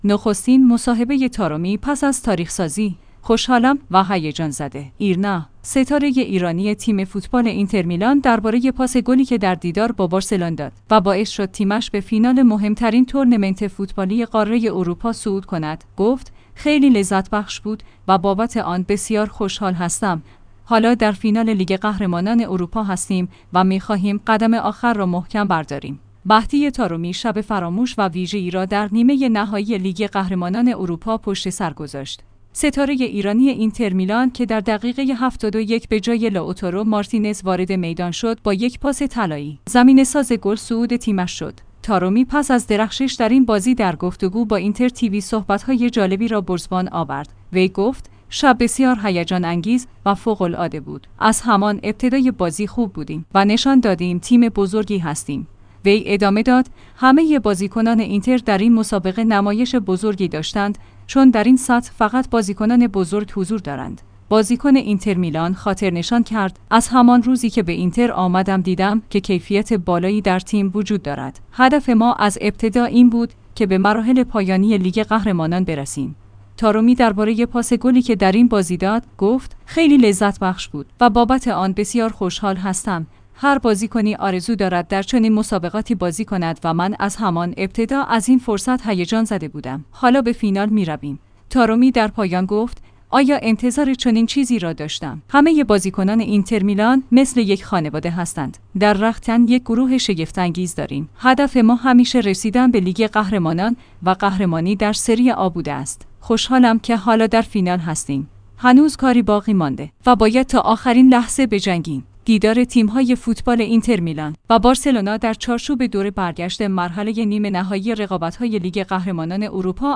نخستین مصاحبه طارمی پس از تاریخ‌سازی: خوشحالم و هیجان‌زده